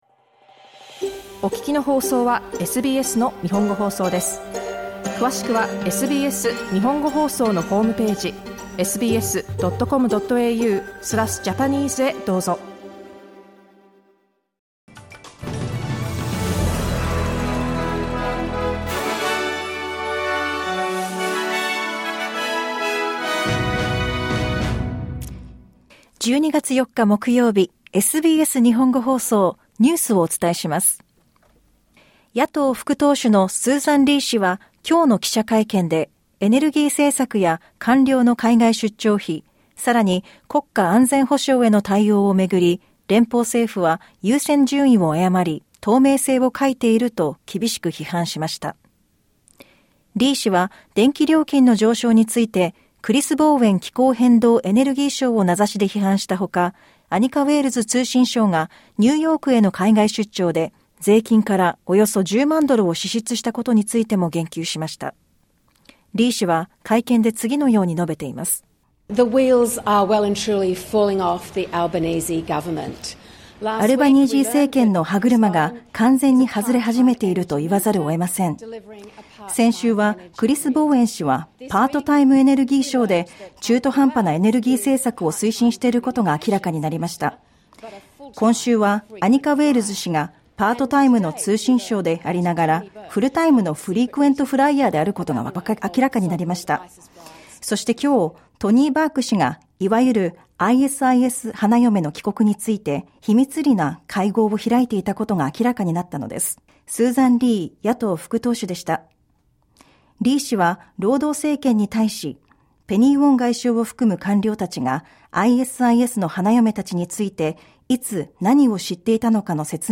SBS日本語放送ニュース12月4日木曜日